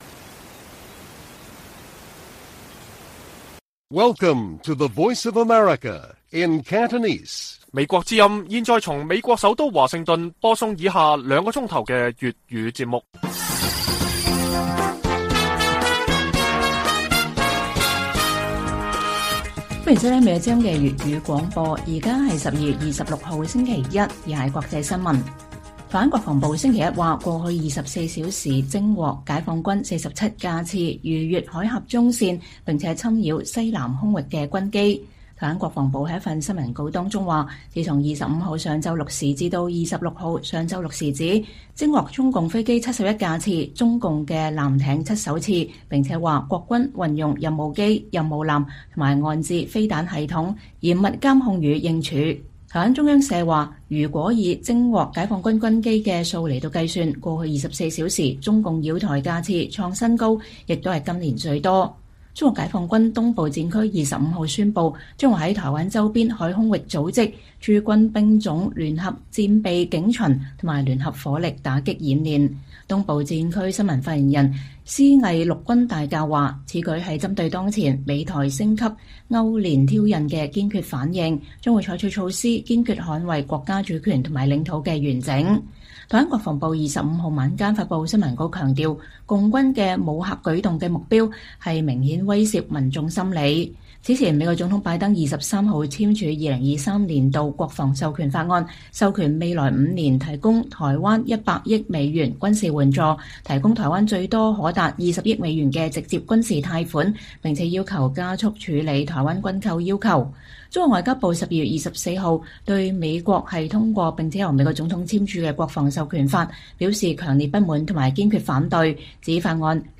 粵語新聞 晚上9-10點：拜登簽署國防授權法後 中國以軍機密集擾台